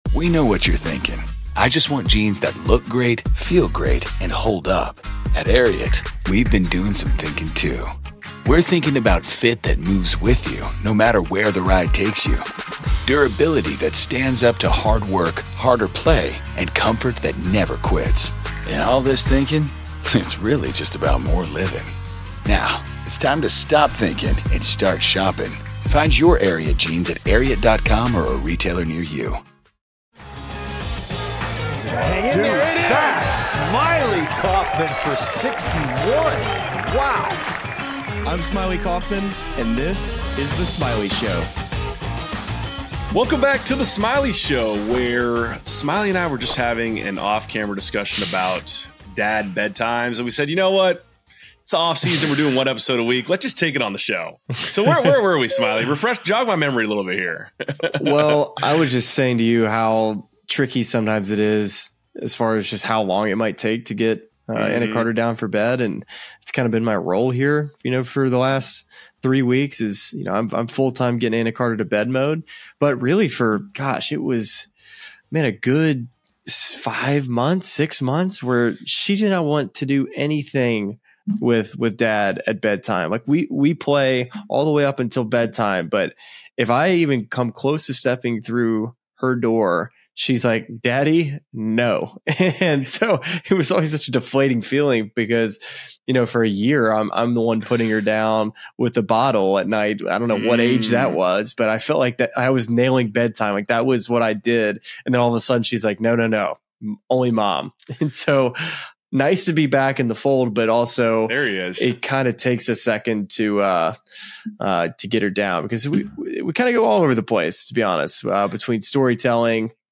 A can’t-miss conversation about the next great name in golf.